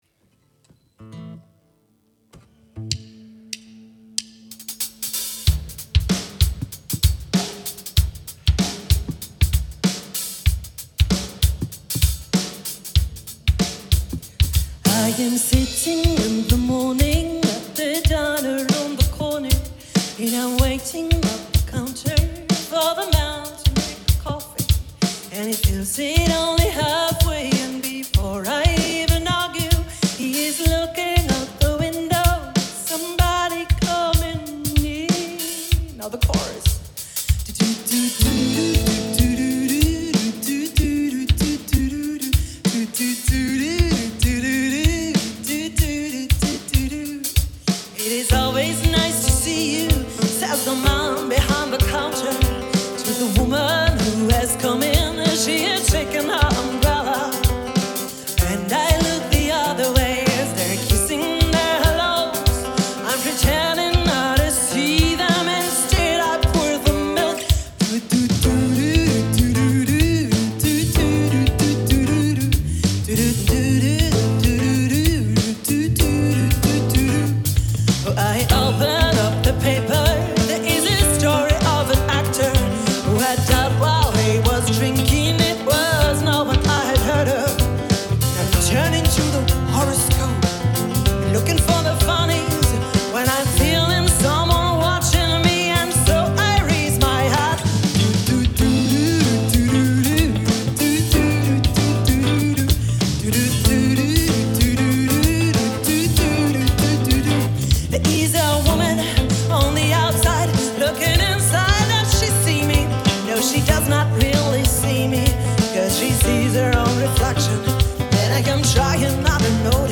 Partyband